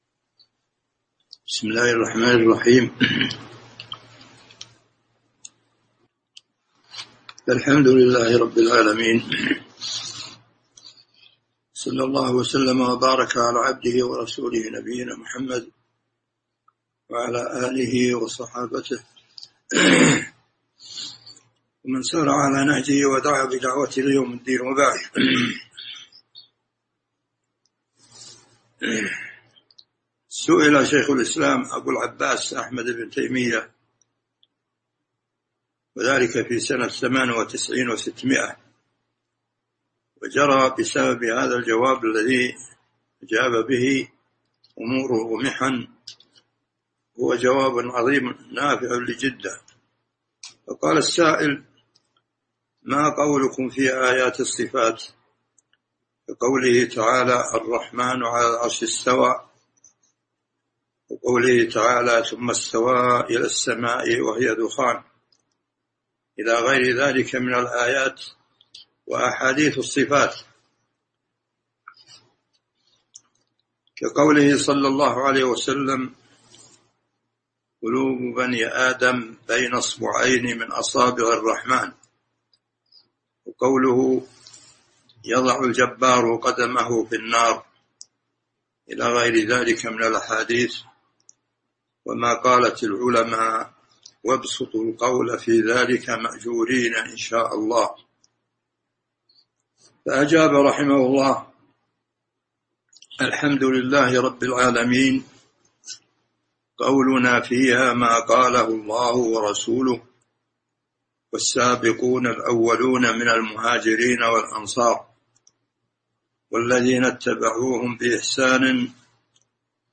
تاريخ النشر ٢ ذو القعدة ١٤٤٢ هـ المكان: المسجد النبوي الشيخ